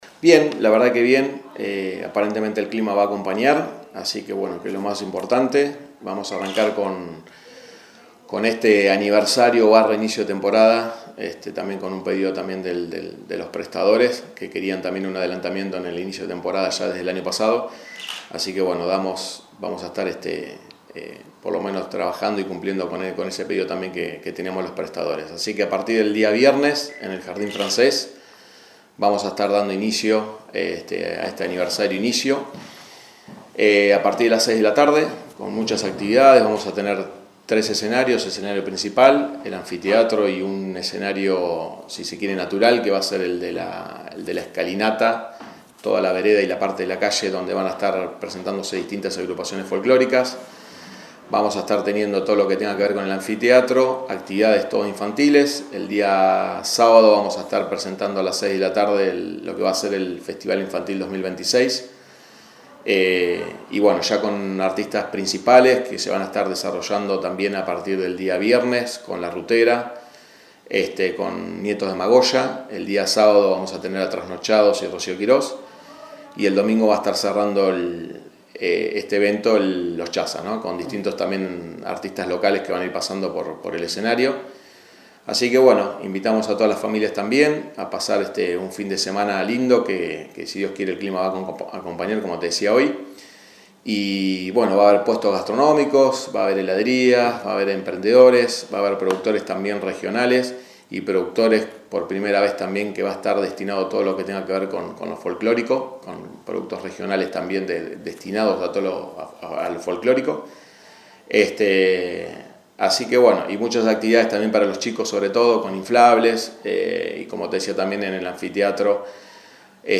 El secretario de Turismo y Desarrollo Productivo brindó detalles a 72 horas del comienzo de las celebraciones en el Jardín Francés del Parque Miguel Lillo.